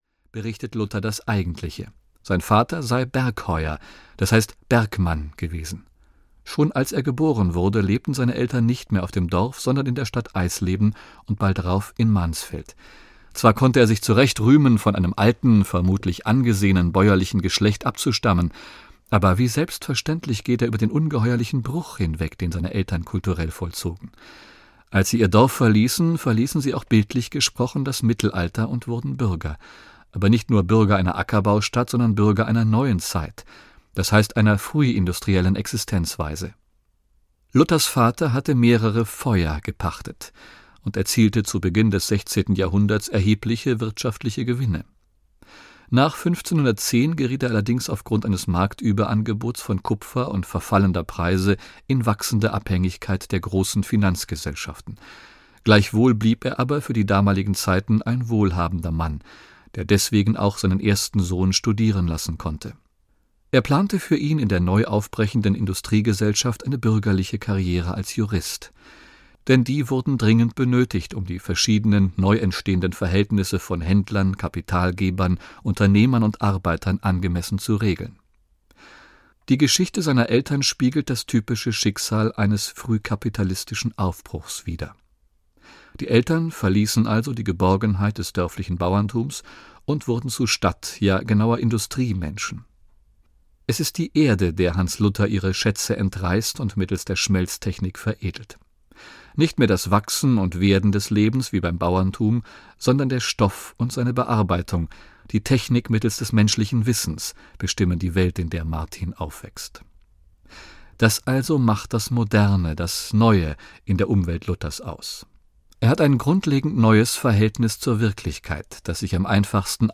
Ein Mensch namens Luther - Georg Gremels - Hörbuch